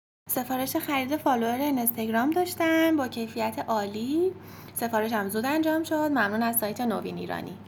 نظرات مثبت مشتریان عزیزمون با صدا خودشون رضایت از سایت نوین ایرانی